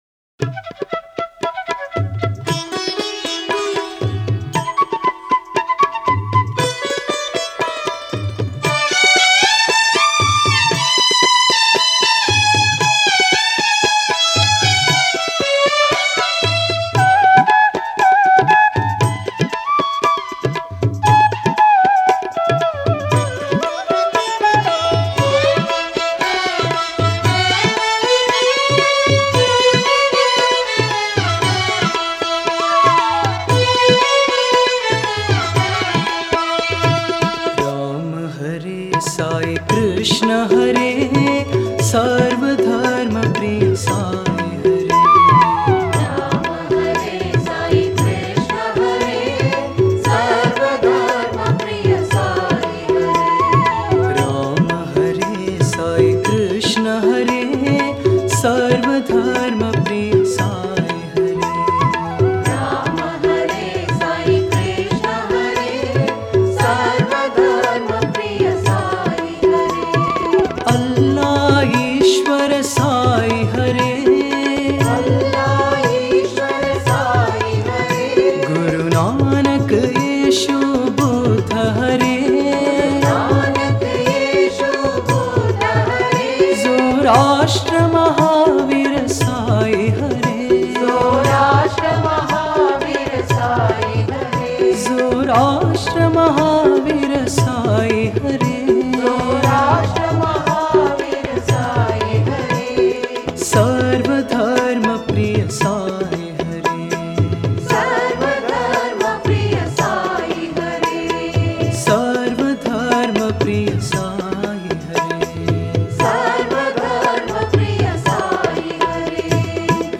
Author adminPosted on Categories Sarva Dharma Bhajans